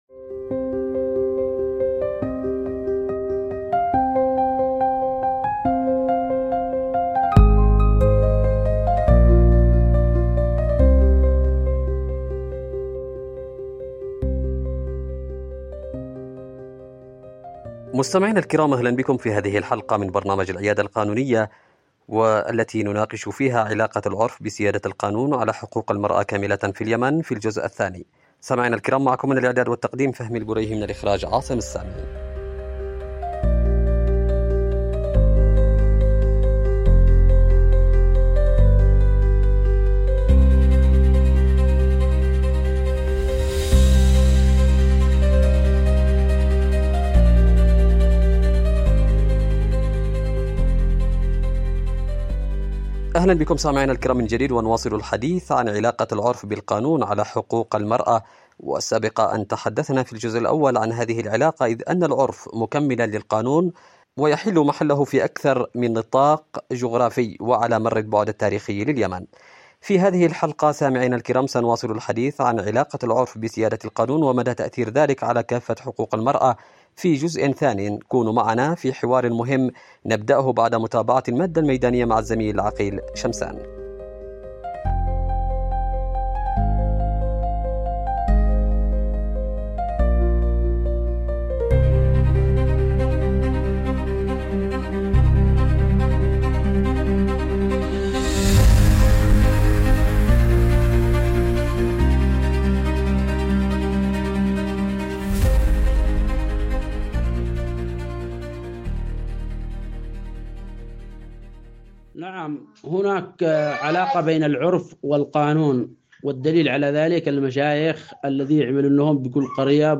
في نقاش قانوني حول التداخل بين العرف والقانون، وتأثيرهما المباشر على واقع المرأة اليمنية.
📻 عبر إذاعة رمز